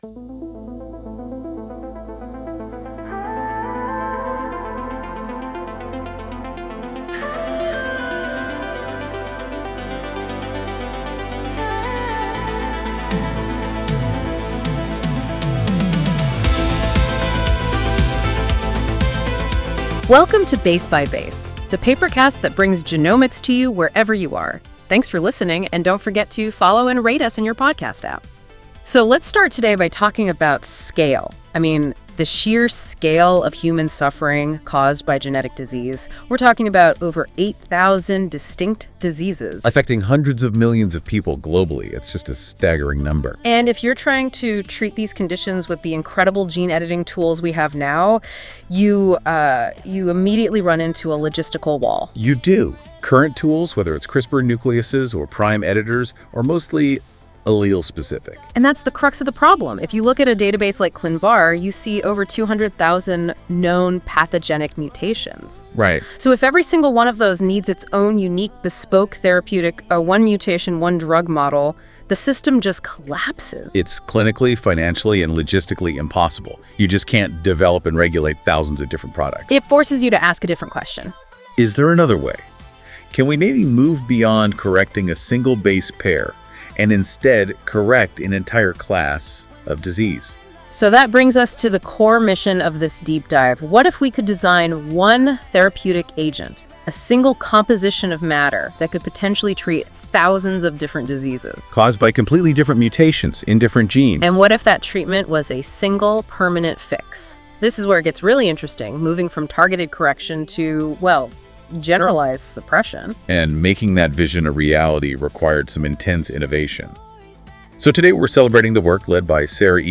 PERT: Prime Editing tRNAs for Nonsense Mutations Music:Enjoy the music based on this article at the end of the episode.